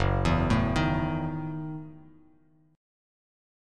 F#m13 Chord
Listen to F#m13 strummed